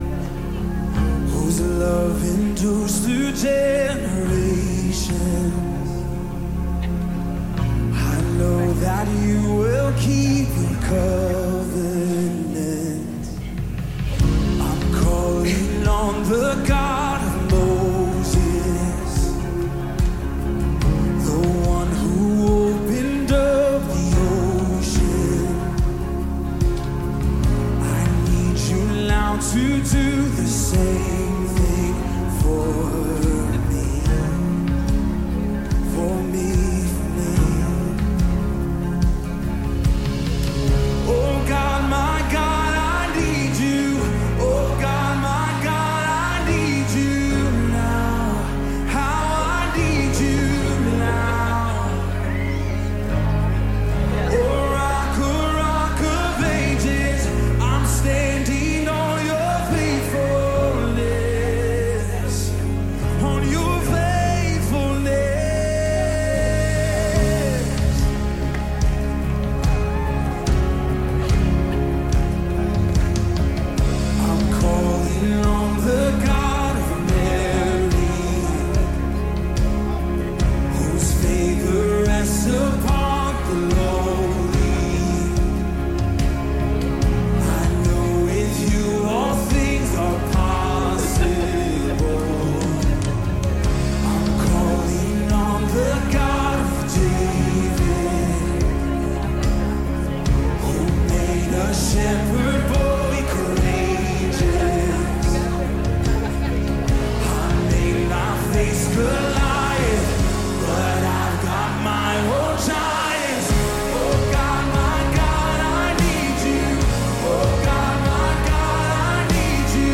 The GO Sermon Series